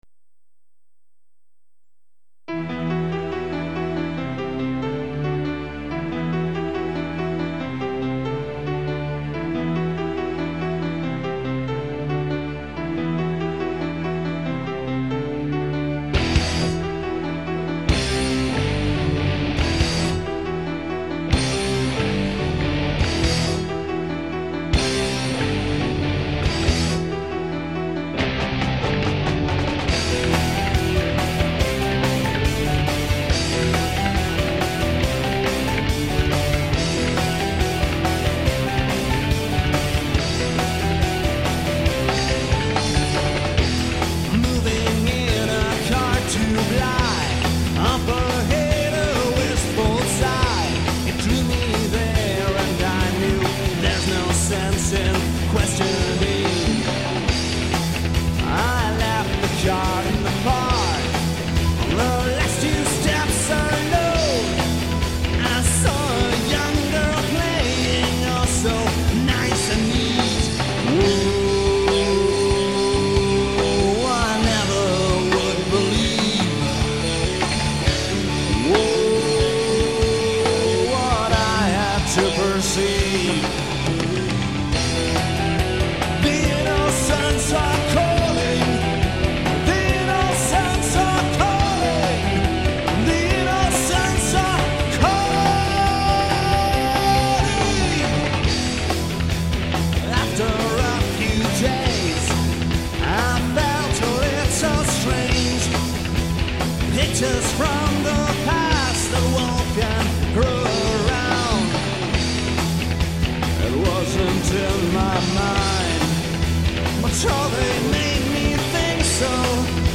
Nu-Melodic Metaller
Vocals
Bass
Drums
Keyboards